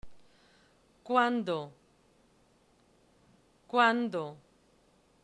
Haz clic en la palabra para oír la pronunciación (variante castellana)
/ k / ca que qui co cu :